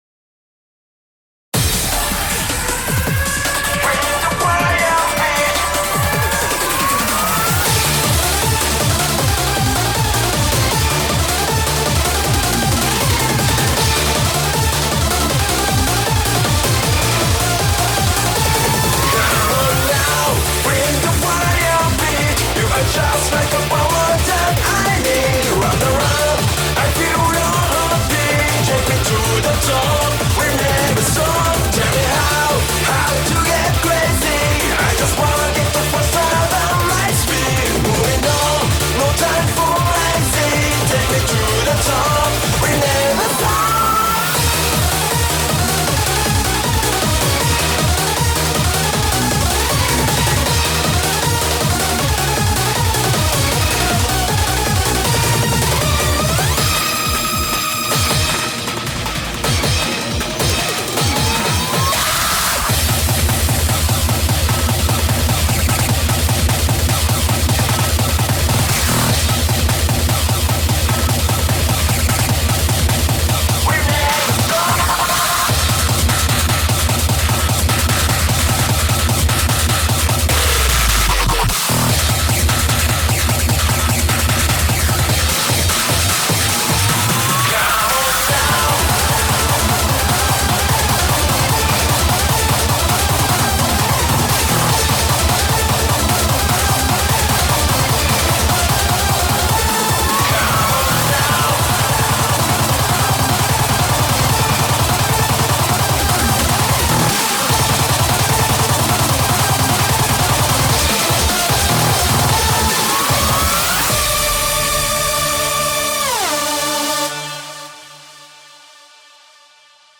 BPM157-300